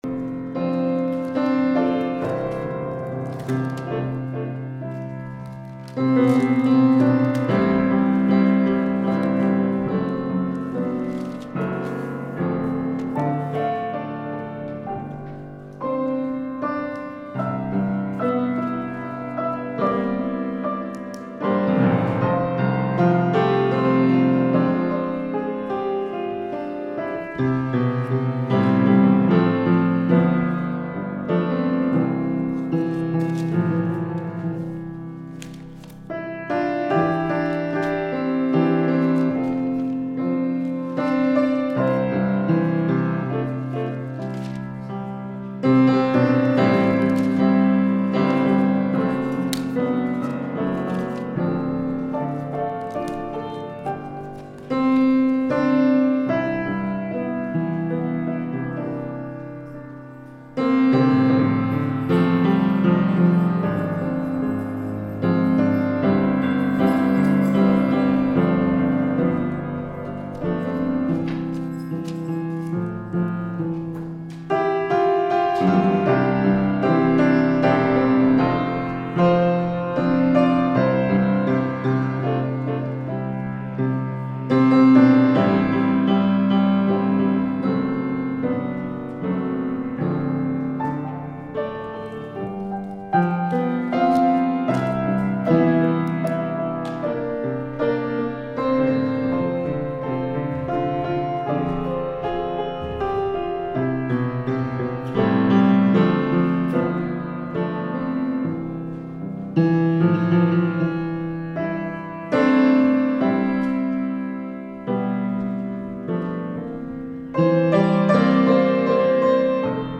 The Lord Is Good – Last Trumpet Ministries – Truth Tabernacle – Sermon Library